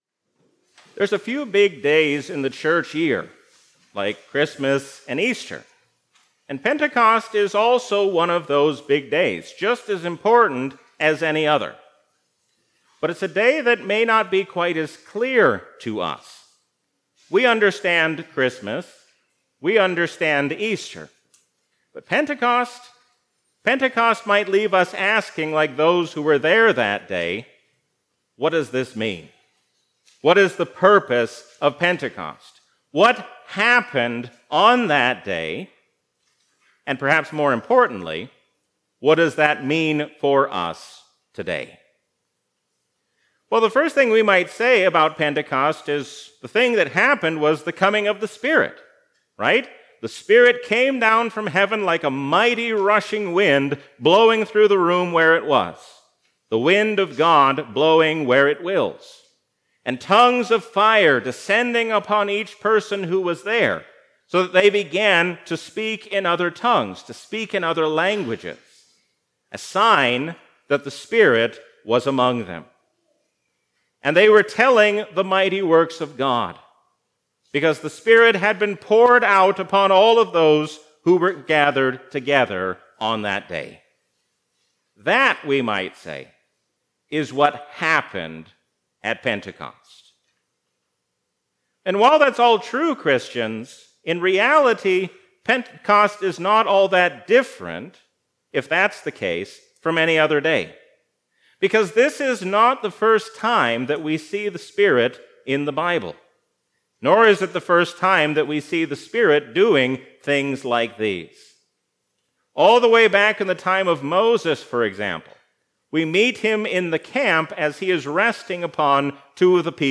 A sermon from the season "Trinity 2024."